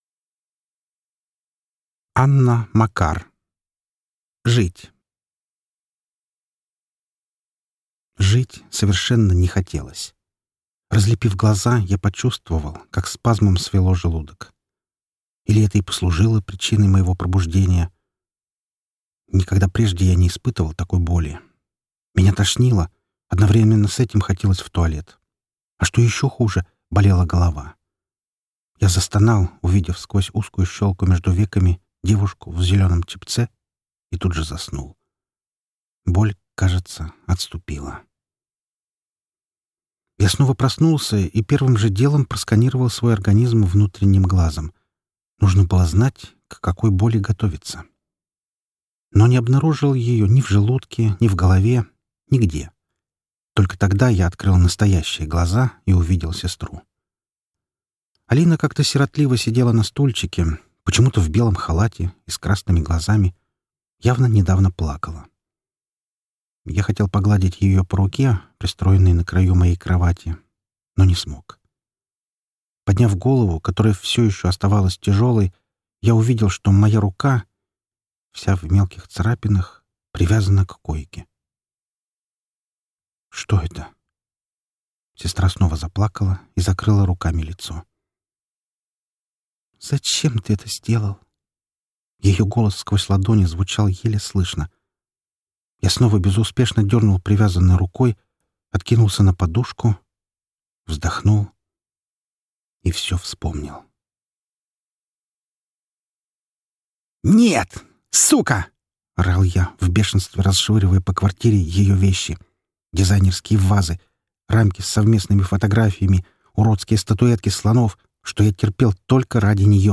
Аудиокнига Жить | Библиотека аудиокниг